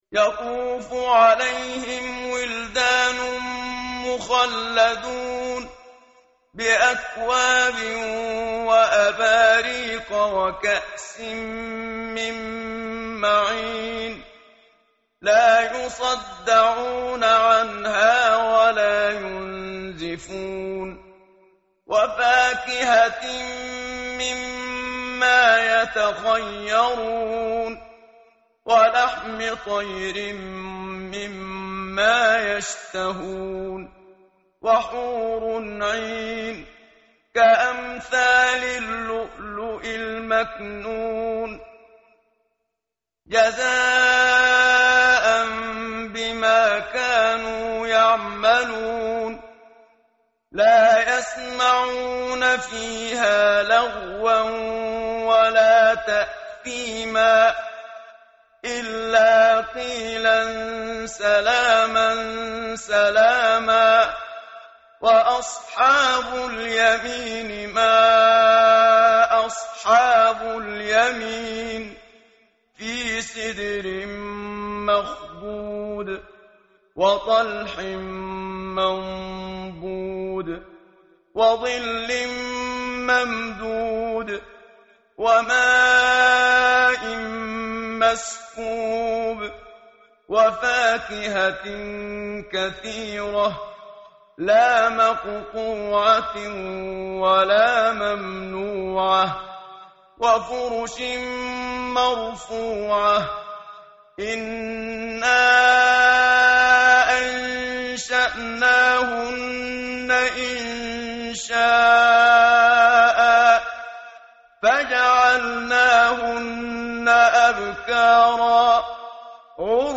متن قرآن همراه باتلاوت قرآن و ترجمه
tartil_menshavi_page_535.mp3